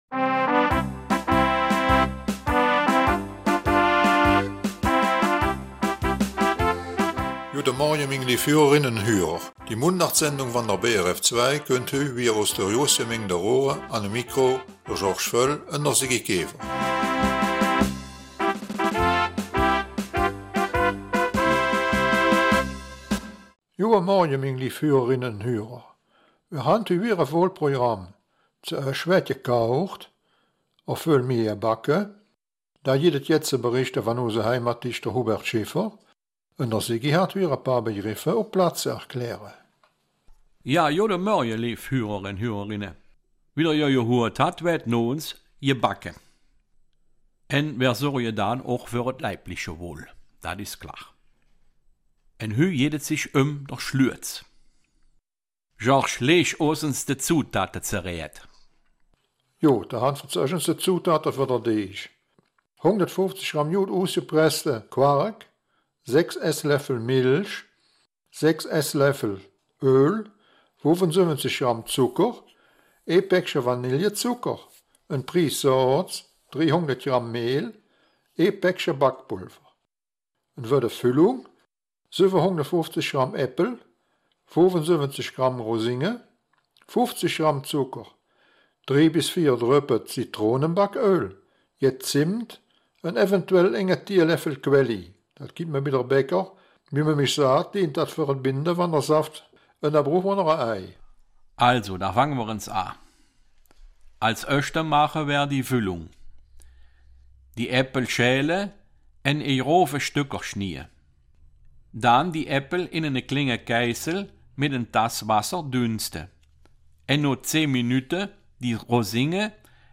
Raerener Mundart - 13. Oktober
Die Mundartsendung vom 13. Oktober aus Raeren bringt folgende Themen: